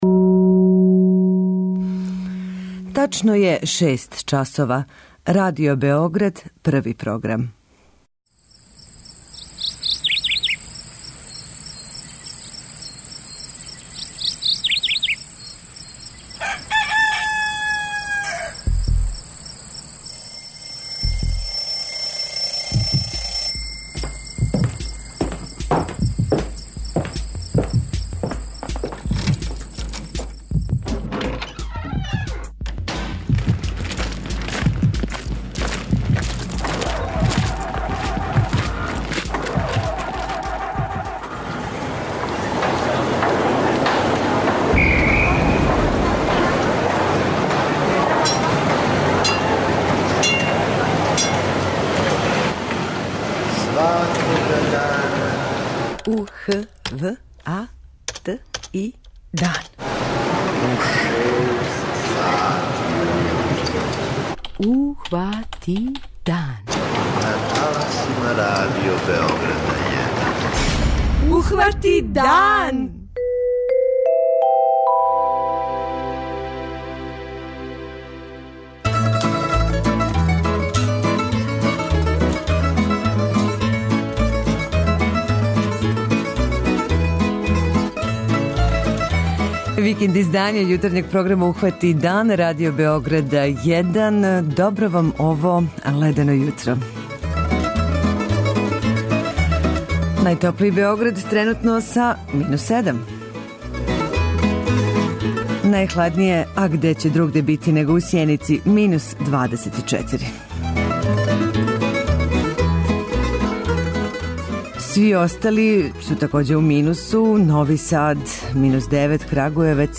преузми : 85.95 MB Ухвати дан Autor: Група аутора Јутарњи програм Радио Београда 1!